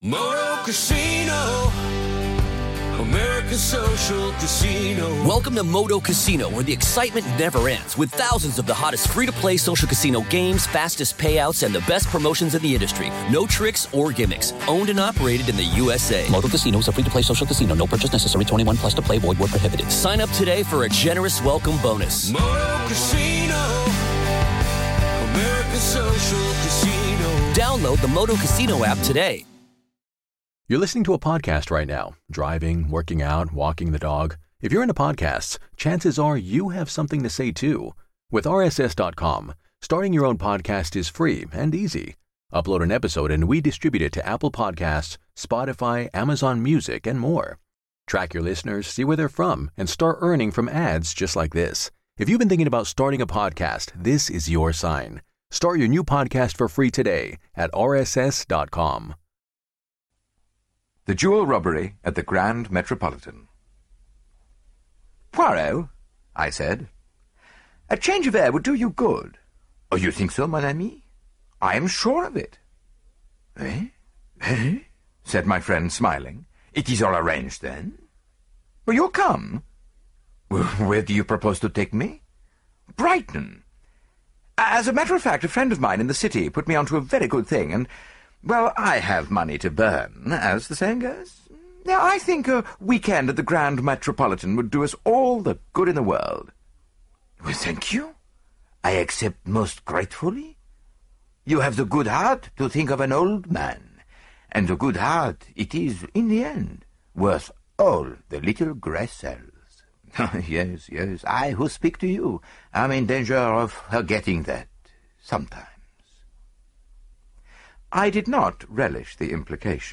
Agatha Christie - Hercule Poirot (Audiobook Collection) Podcast - Agatha Christie - Hercule Poirot 15 - The Jewel Robbery at the Grand Metropolitan (1921) | Free Listening on Podbean App